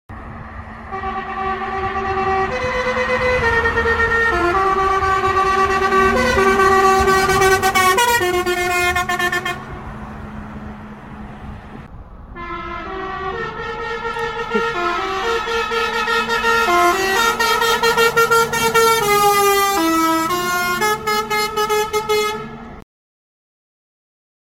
Peru Fire Department Responding X2 Sound Effects Free Download